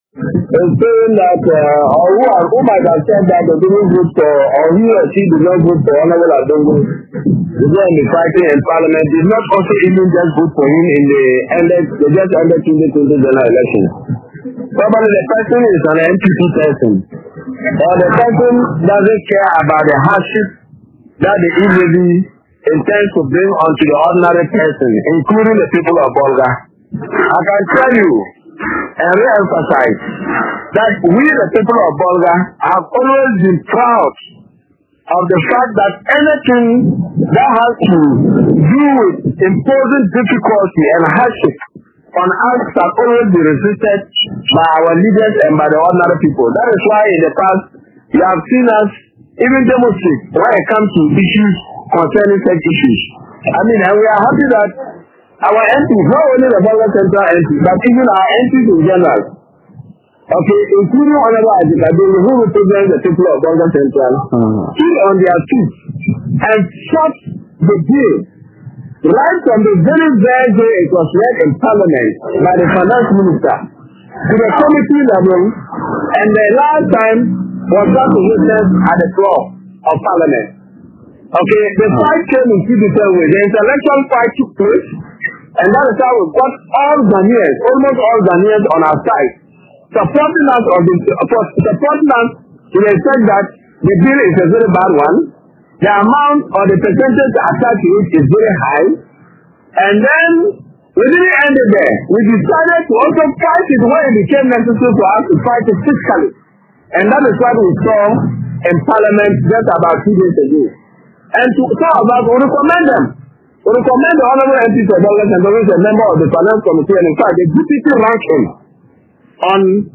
Below are some of the voices from residents: